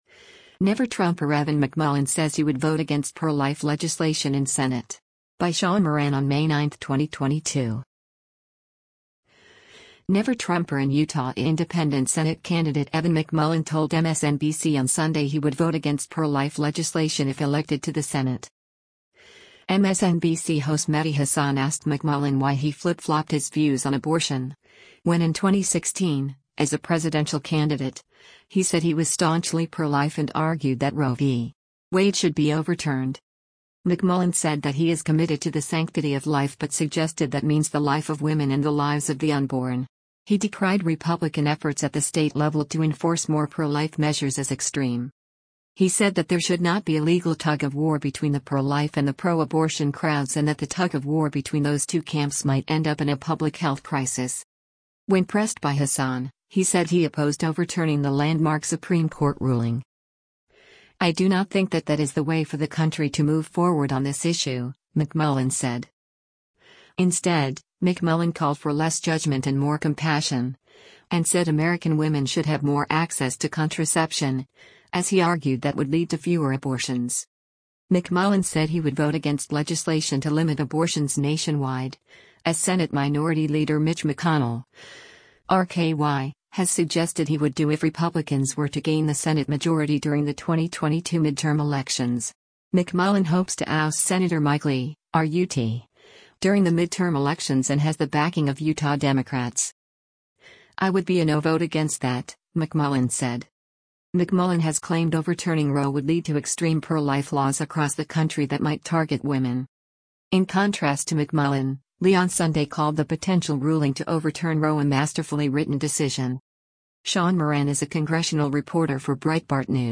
MSNBC host Mehdi Hasan asked McMullin why he flip-flopped his views on abortion, when in 2016, as a presidential candidate, he said he was staunchly pro-life and argued that Roe v. Wade should be overturned.